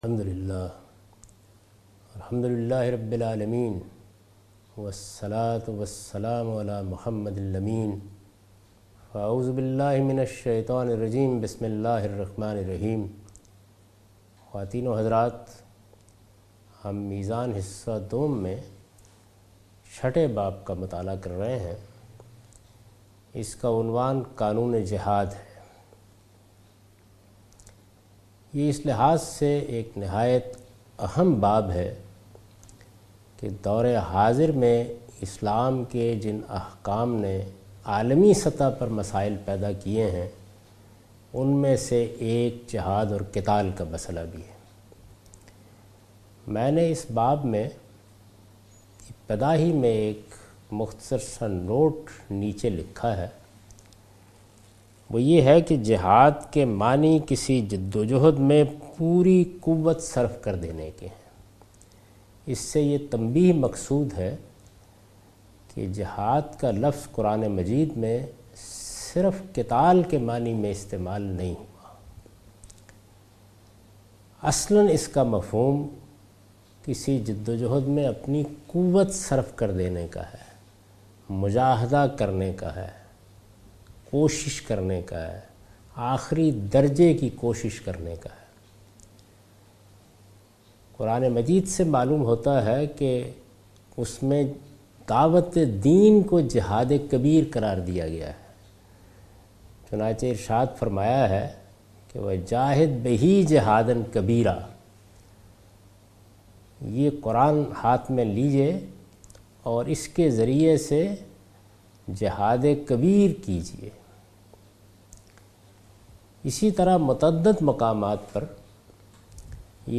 A comprehensive course taught by Javed Ahmed Ghamidi on his book Meezan. In this lecture series he will teach The Shari'ah of Jiahd. This is an introduction to the topic.